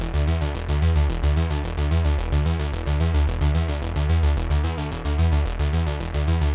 Index of /musicradar/dystopian-drone-samples/Droney Arps/110bpm
DD_DroneyArp4_110-E.wav